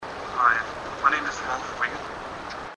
Voice EVP